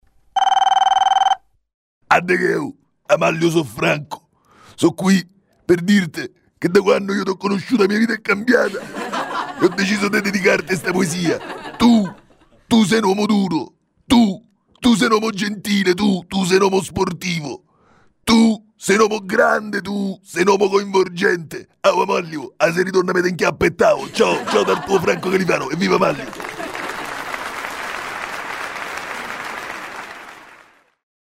In allegato al libro 'Dai la pappa al bimbo' troverete dei files audio quindi sarà possibile apprezzare qualche pagina di audio-libro; nell'occasione provate a leggere e a sentire i frequentatori ...